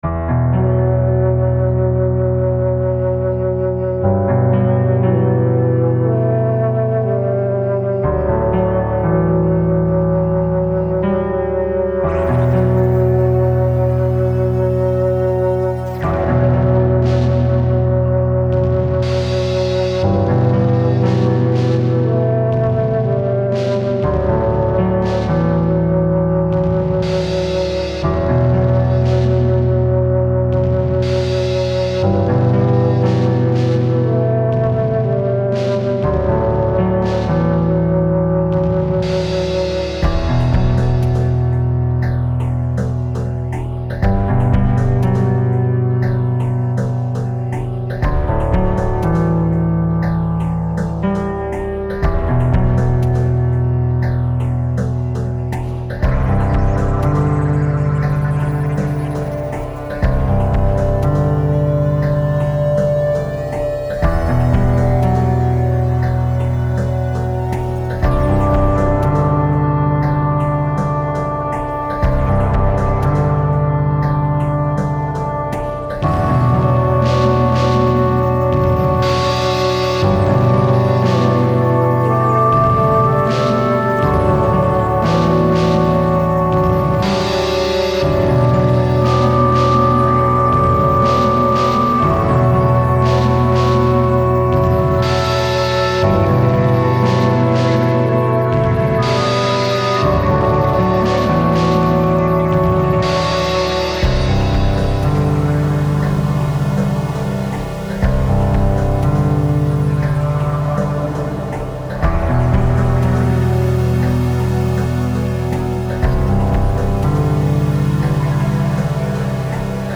downbeat style